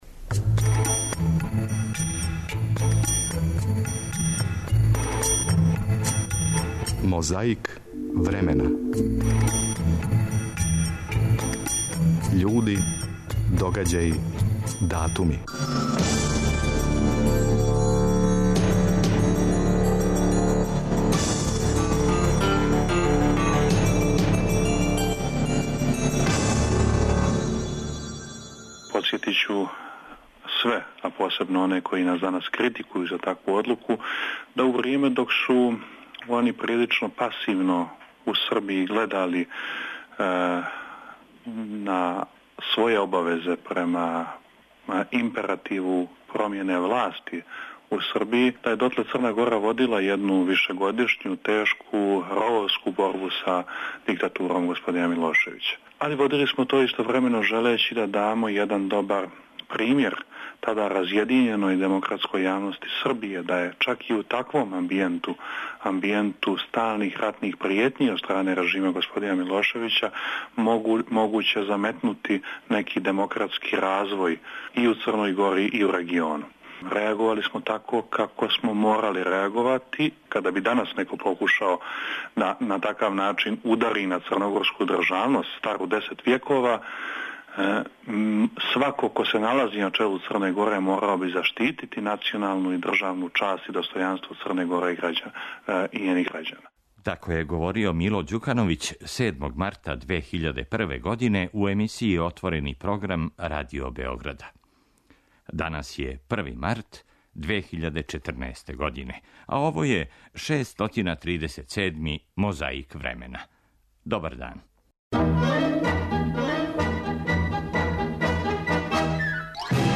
Том приликом говорио члан Председништва, Лазар Мојсов.
О новонасталој ситуацији, у студију Радио телевизије Сарајево, разговарали су Биљана Плавшић, Фрањо Борас и Велибор Остојић.